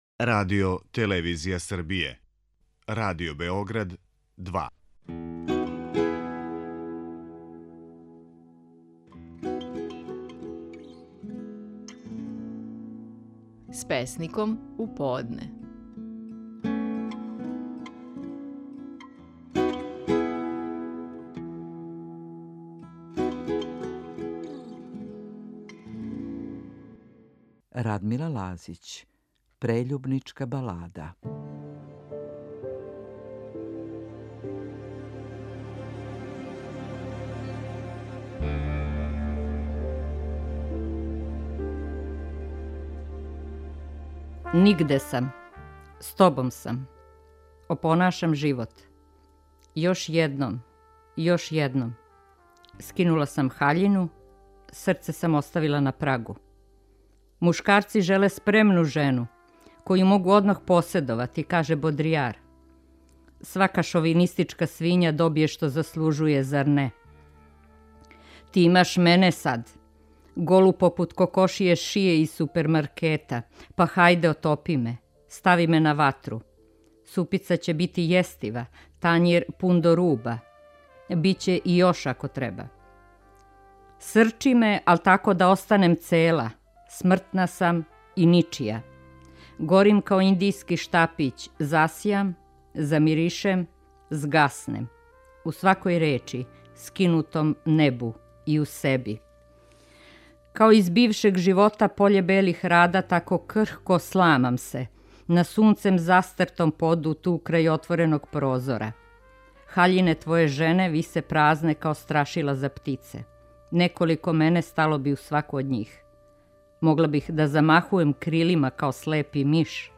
Стихови наших најпознатијих песника, у интерпретацији аутора.
Радмила Лазић говори стихове под називом „Прељубничка балада".